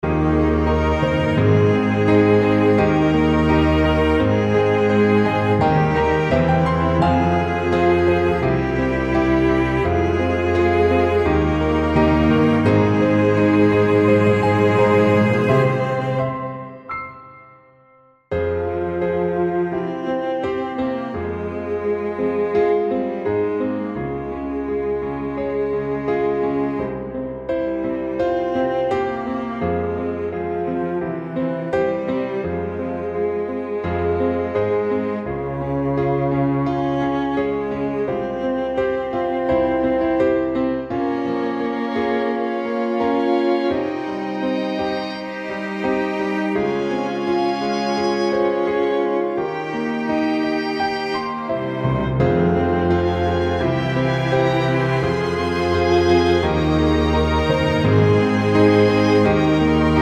Up 4 Semitones For Male